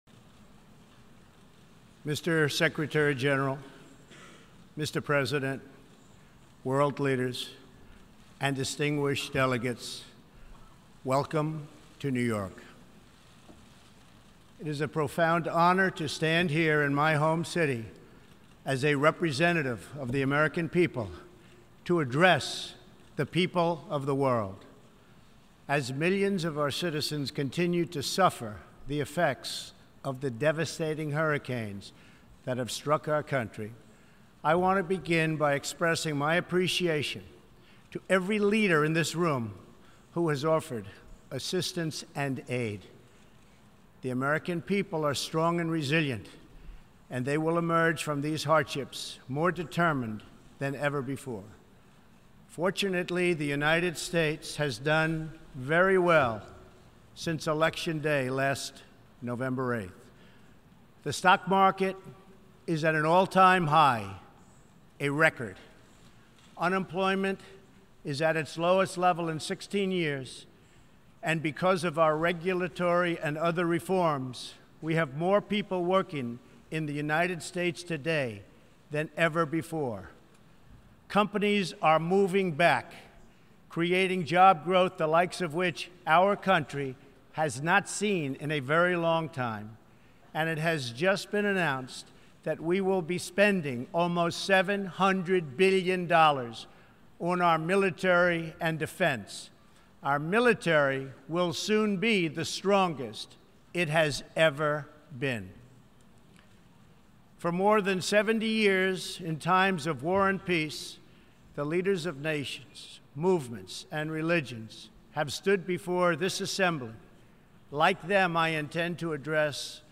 September 19, 2017: Address to the United Nations General Assembly
President_Trump_Gives_an_Address_72nd_Session_United_Nations_General_Assembly.mp3